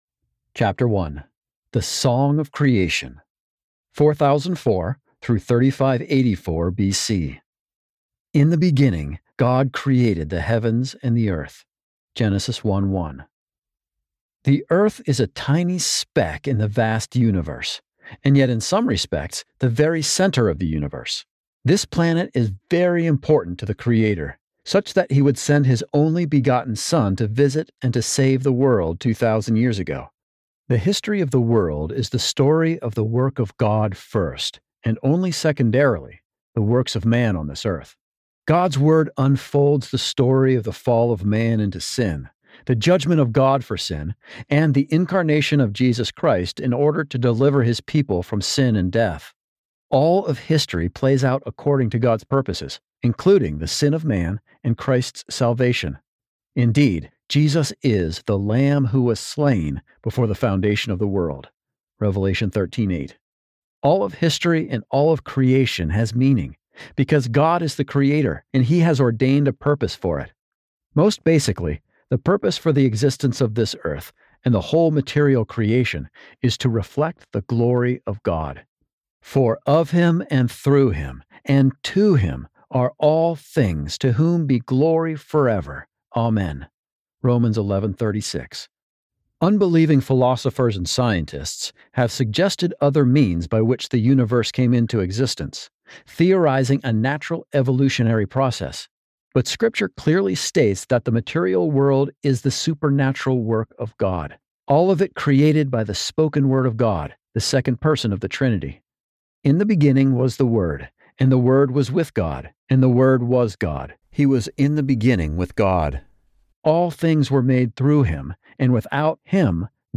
Audiobook Download, 17 hours 25 minutes